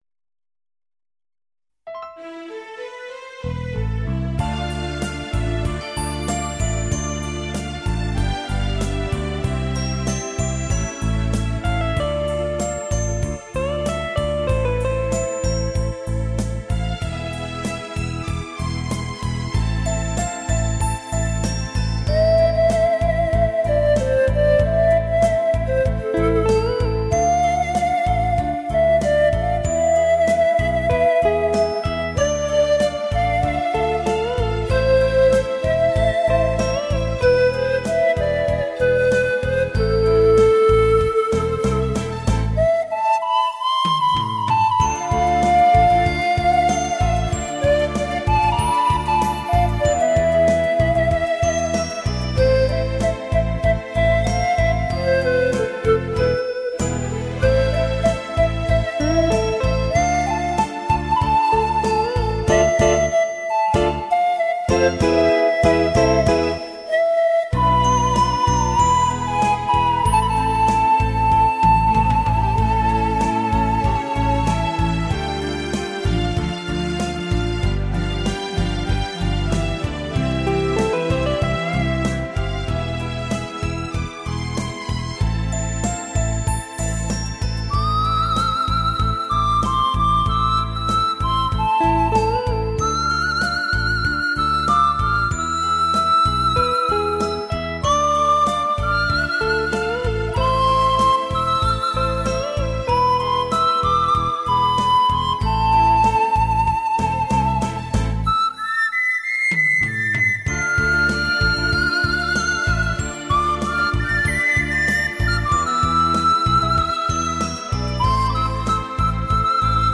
排箫演奏